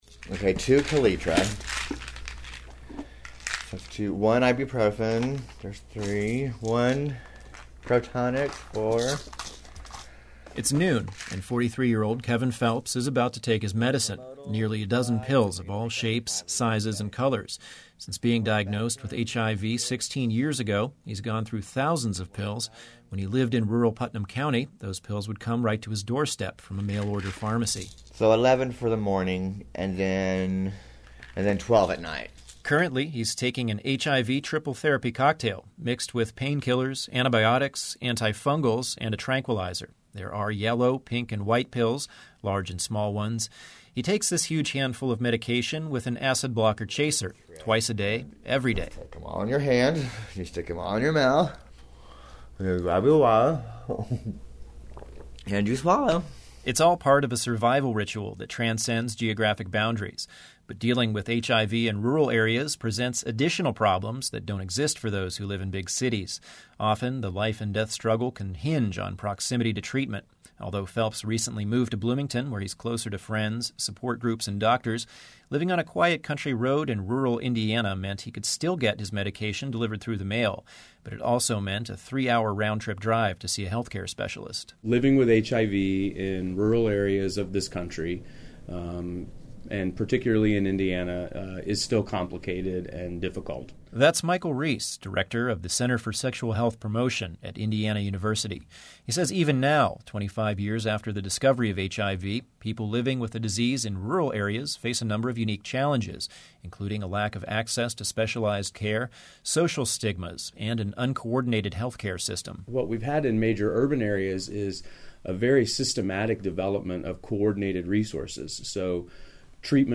Indiana Public Media is your source for news and information, music, arts and community events from WFIU Public Radio and WTIU Public Television